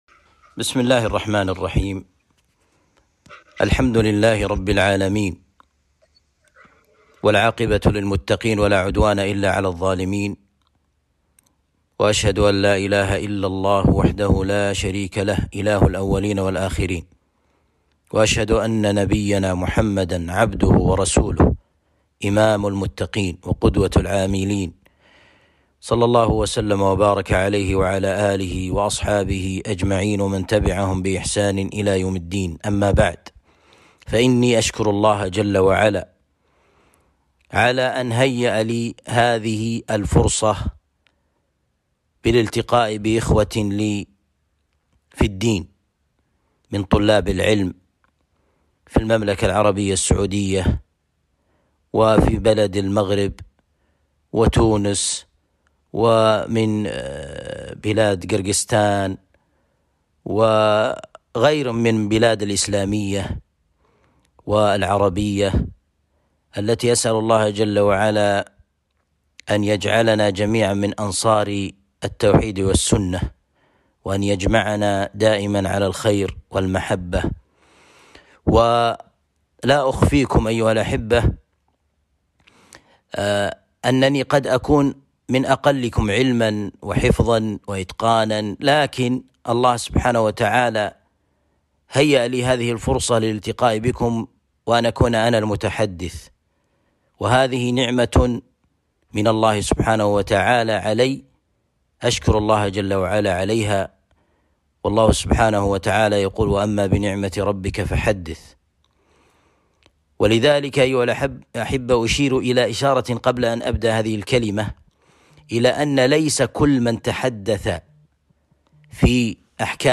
كلمة توجيهية بعنوان الوصايا الثلاث